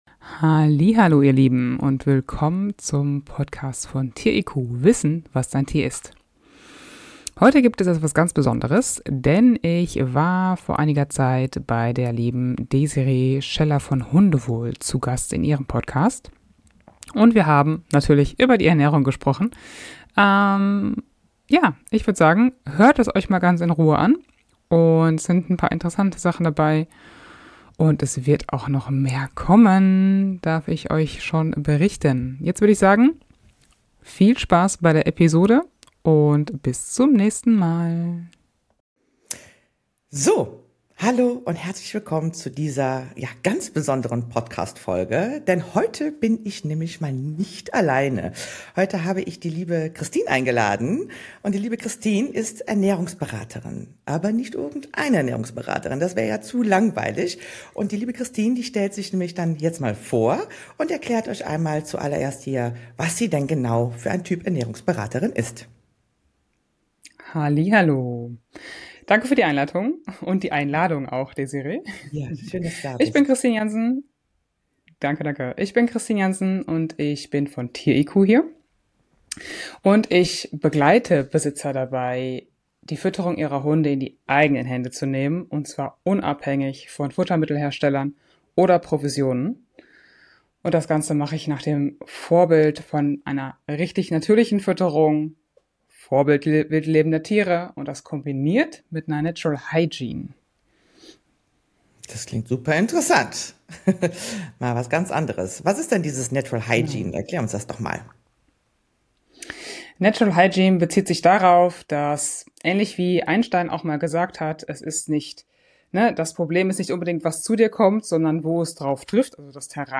Natürliche Hundeernährung – Interview